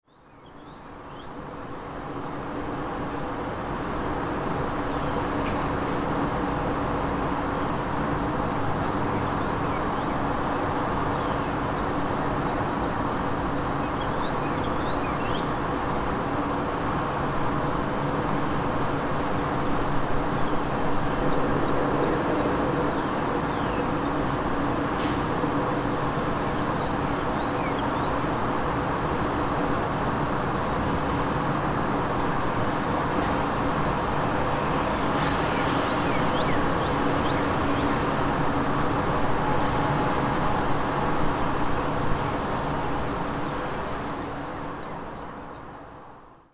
دانلود آهنگ باد 55 از افکت صوتی طبیعت و محیط
دانلود صدای باد 55 از ساعد نیوز با لینک مستقیم و کیفیت بالا
جلوه های صوتی